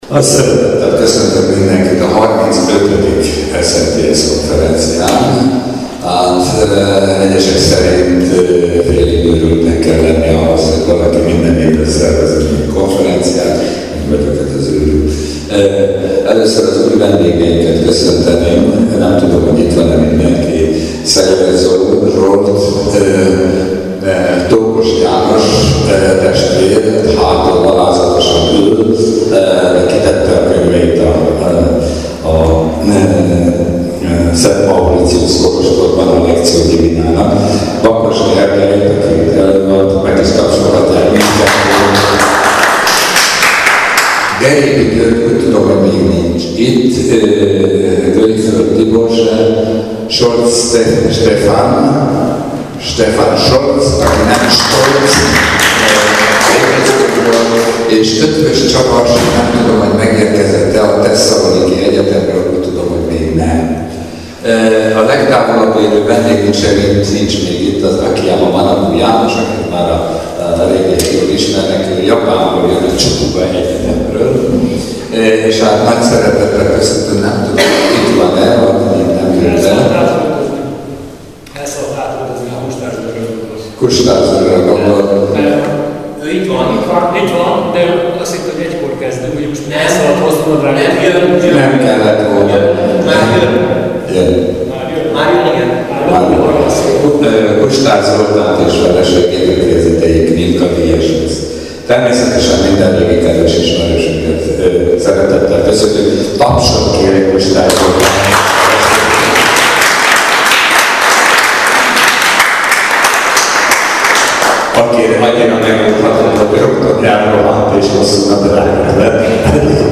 A KONFERENCIÁN KÉSZÜLT HANGFELVÉTGELEK (mp3)